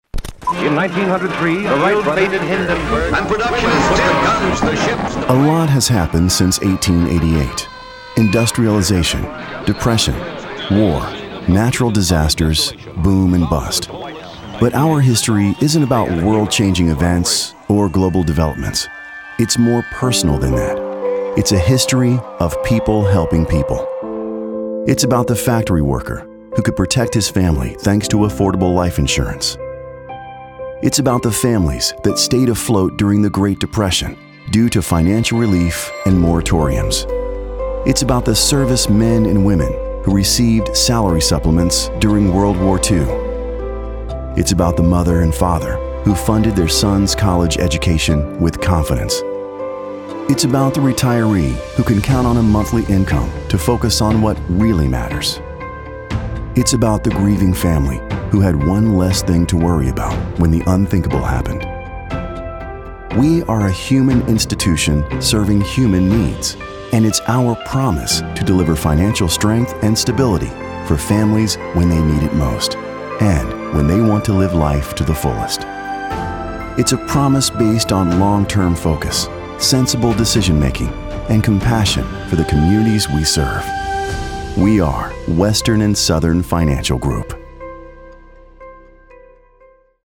Documentary Announcer